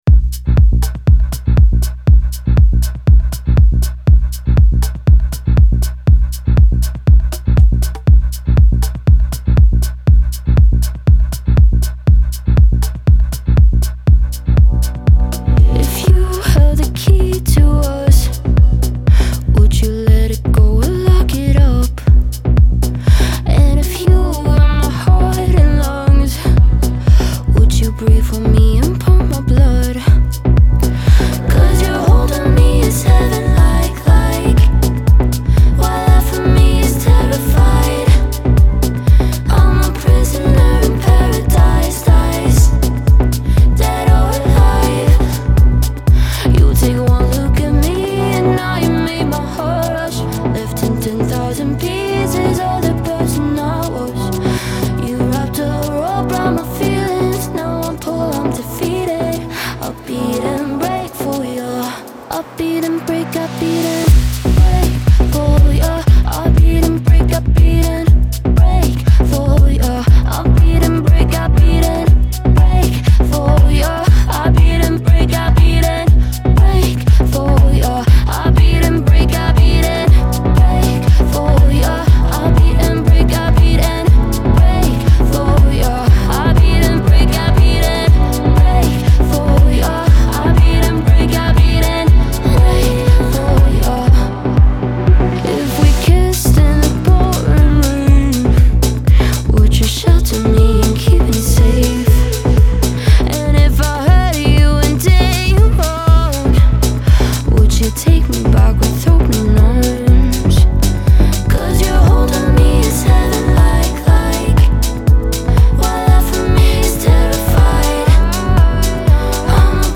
Genre : Électronique, Dance